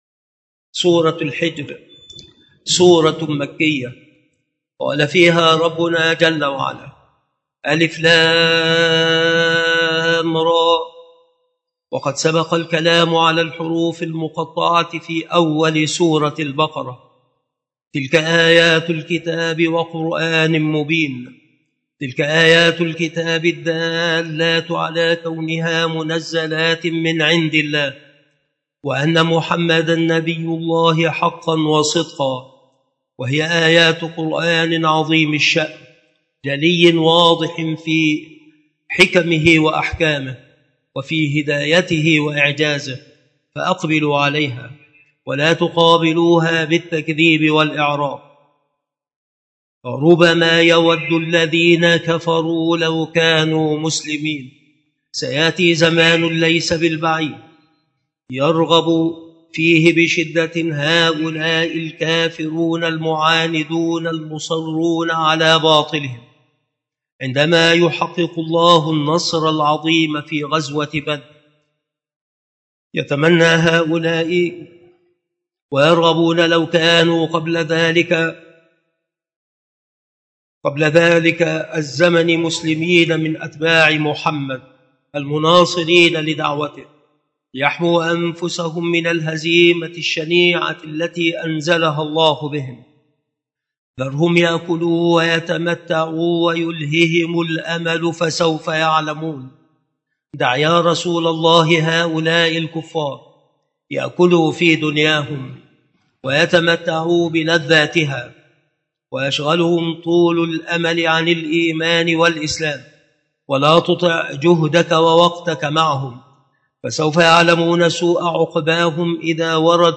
التفسير
مكان إلقاء هذه المحاضرة بالمسجد الشرقي بسبك الأحد - أشمون - محافظة المنوفية - مصر